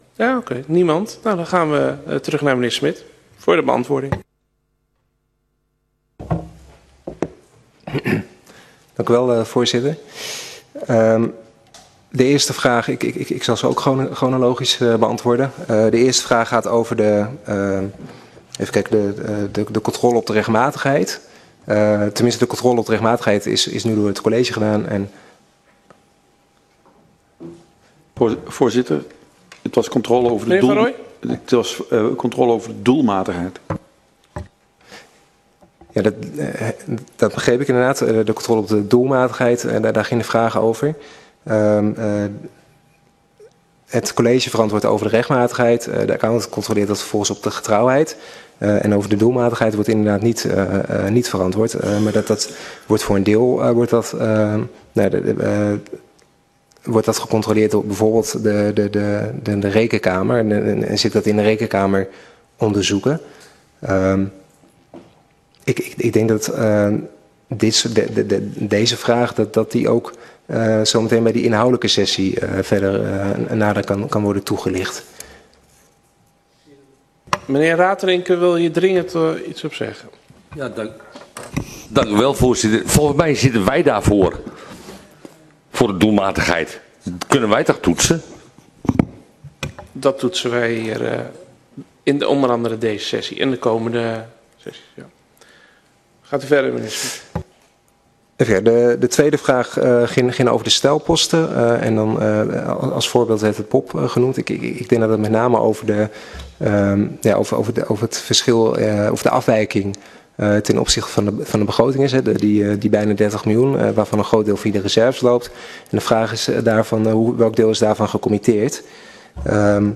Locatie: Statenzaal
De beeldvorming bestaat uit een presentatie van het accountantsverslag en de jaarstukken op hoofdlijnen.